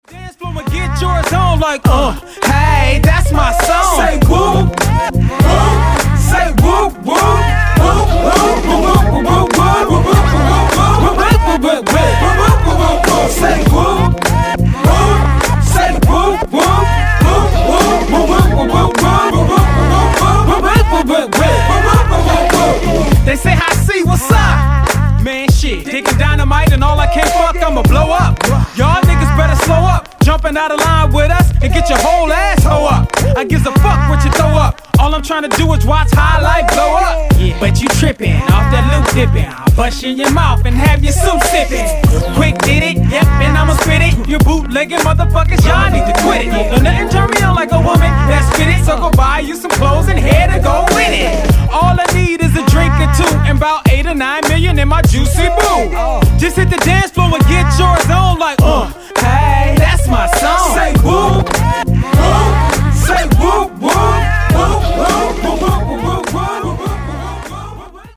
Der Opener ist gleich ein Banger der Extraklasse.